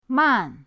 man4.mp3